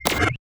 UIClick_Menu Whistle Whoosh Pitch Up.wav